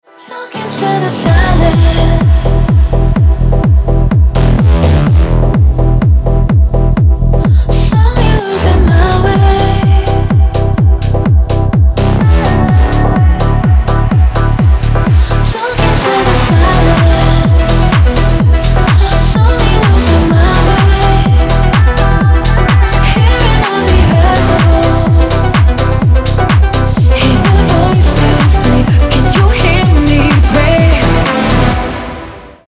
• Качество: 320, Stereo
красивый женский голос
Стиль: future house